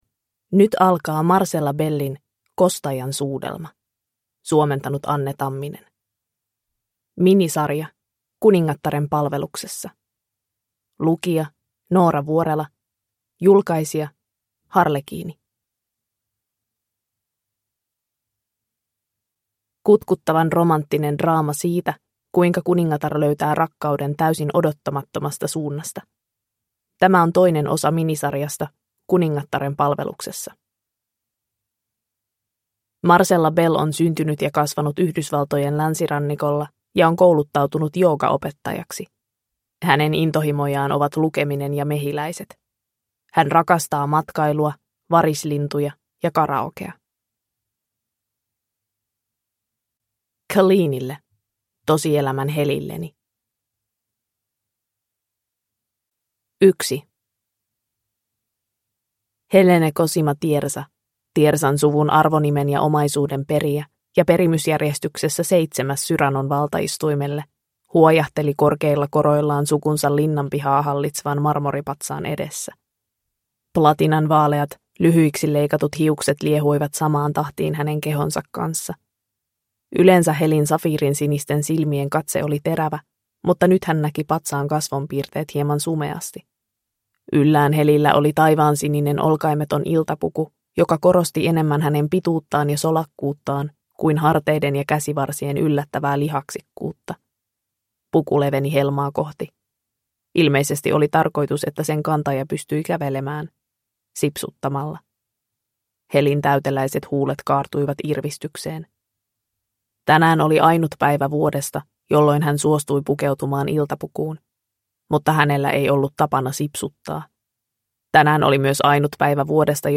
Kostajan suudelma (ljudbok) av Marcella Bell